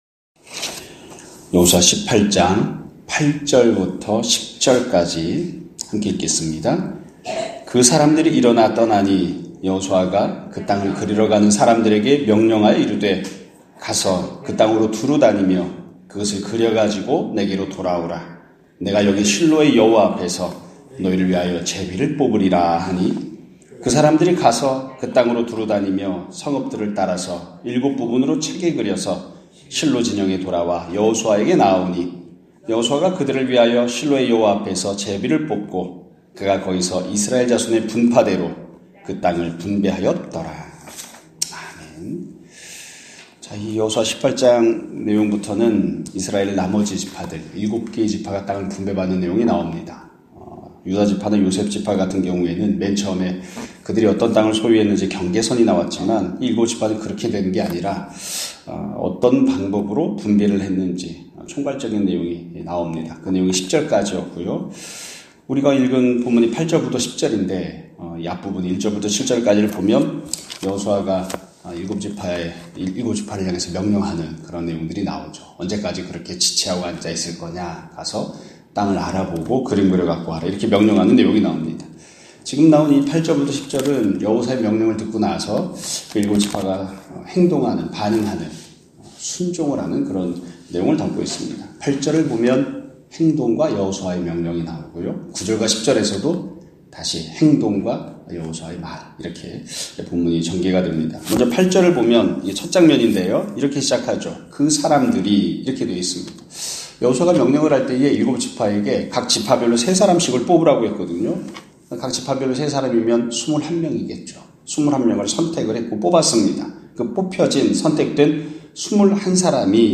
2024년 12월 26일(목요일) <아침예배> 설교입니다.